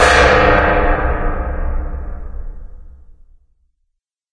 Звук звонкий удар по металу.